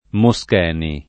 [ mo S k $ ni ]